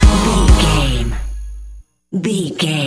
Aeolian/Minor
A♭
Fast
drum machine
synthesiser